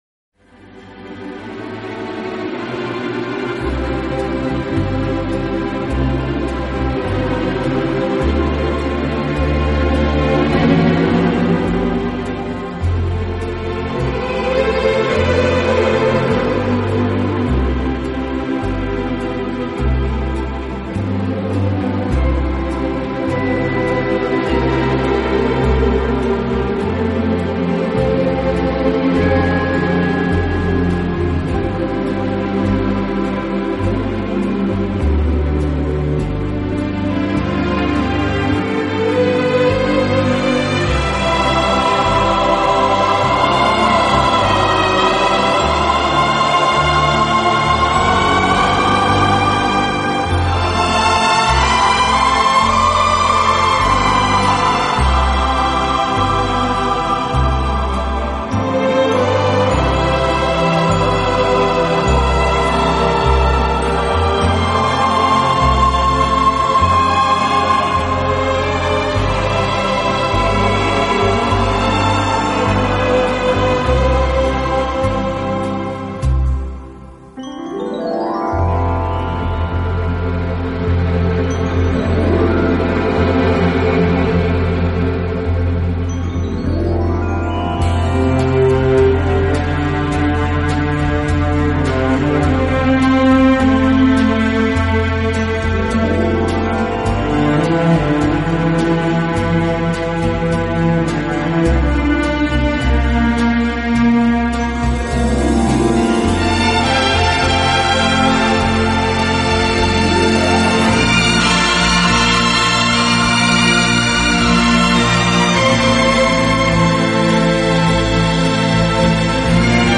此外，这个乐队还配置了一支训练有素、和声优美的伴唱、合唱队。